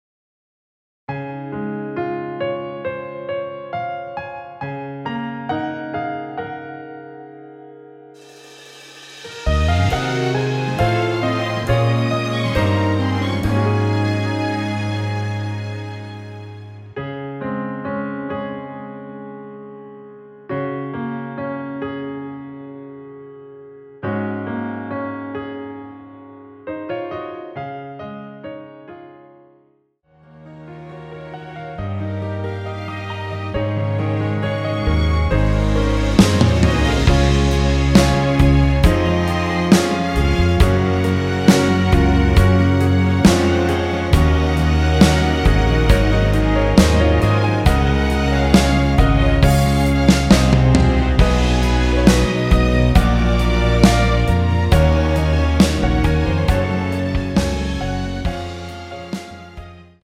여자키에서(-2) 내린 MR입니다.
Db
앞부분30초, 뒷부분30초씩 편집해서 올려 드리고 있습니다.